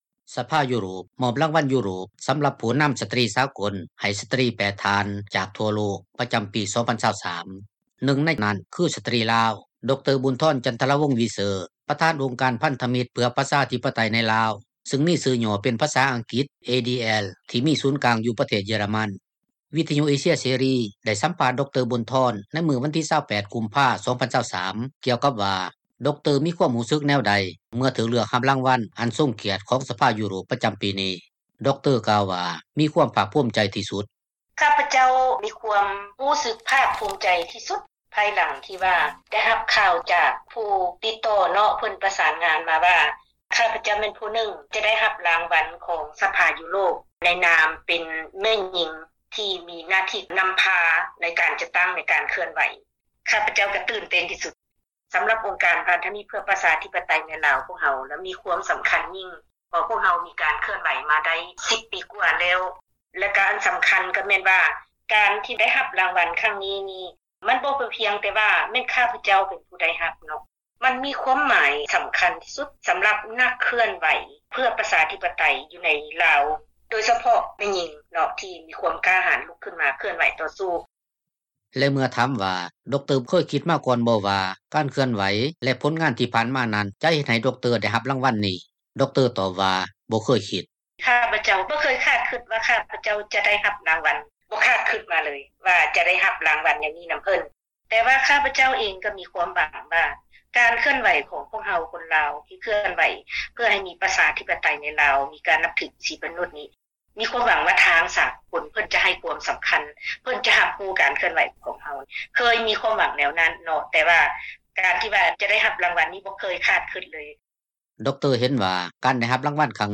ສັມພາດພິເສດ